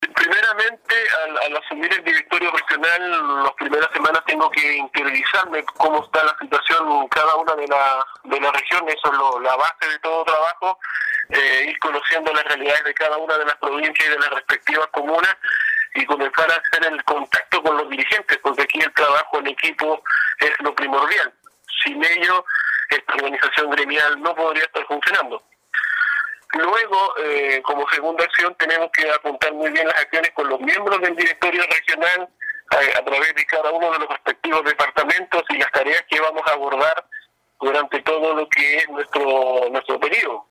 En diálogo con radio Estrella del Mar, sostuvo que espera asumir el cargo conociendo en primera instancia la situación de la institución y del estado de cada una de las comunas que componen esta importante entidad.